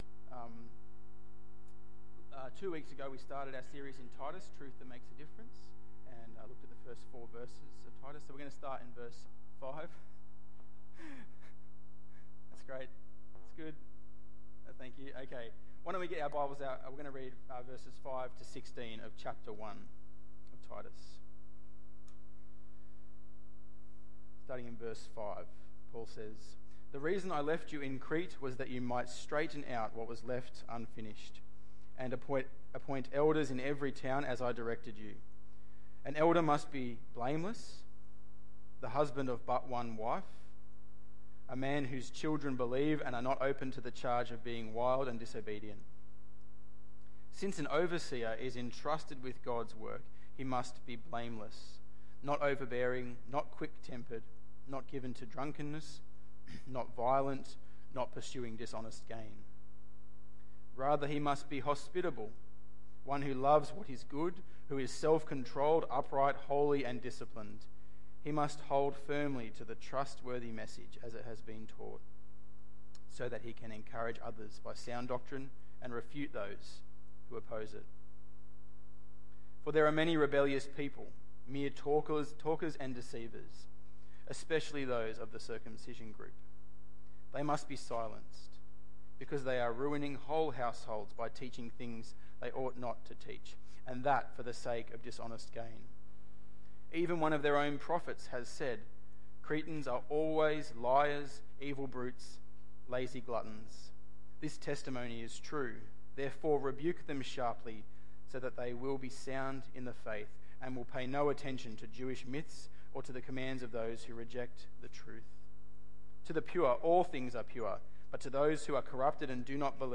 Titus 1:5-16 Tagged with Sunday Evening